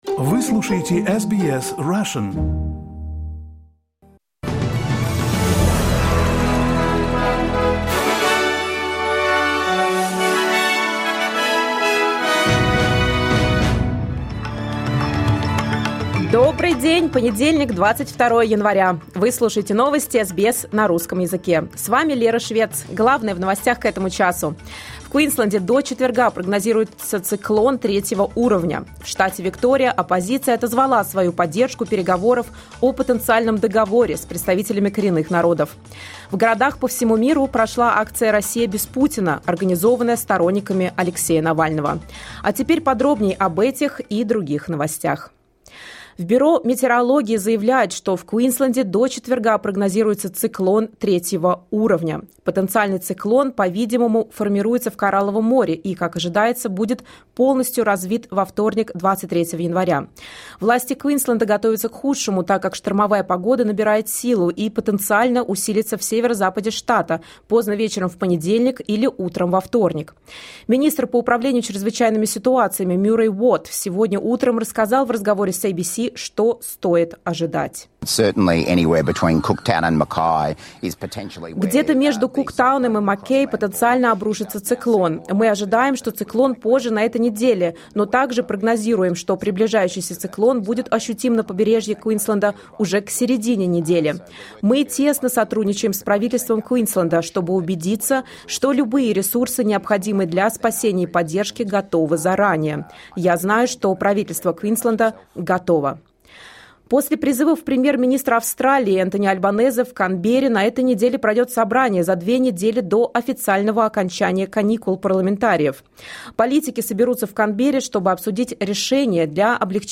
SBS news in Russian — 22.01.2024